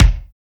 TEKSTER KIK.wav